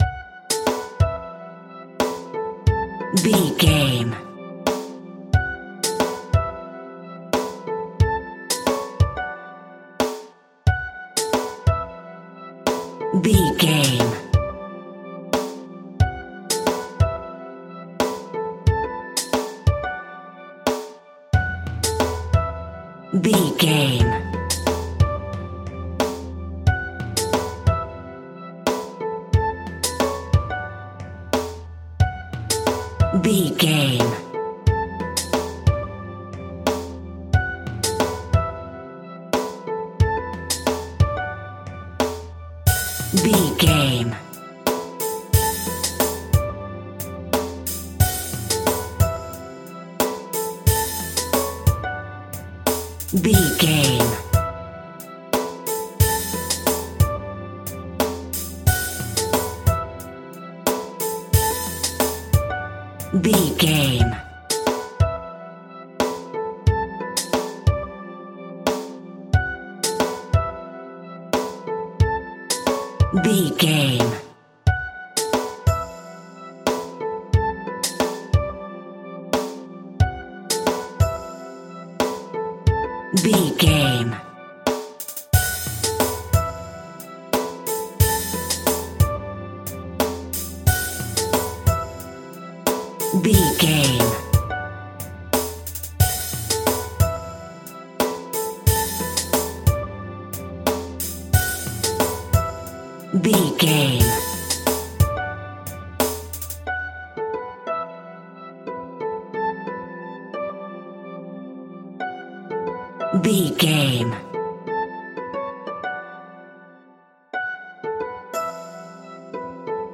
Ionian/Major
D
sitar
bongos
sarod
tambura